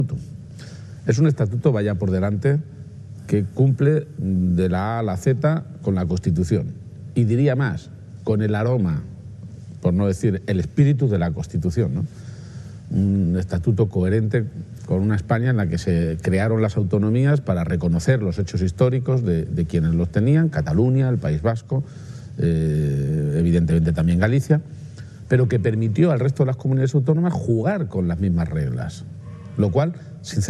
Presidente Martes, 23 Septiembre 2025 - 11:15am El jefe del Ejecutivo regional ha asegurado hoy en CMMedia que el Estatuto de Autonomía, que defenderá el próximo mes de octubre en el Congreso de los Diputados, cumple plenamente con los mandatos de la Constitución española. garcia-page_estauto_autonomia.mp3 Descargar: Descargar